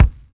DISCO 13 BD.wav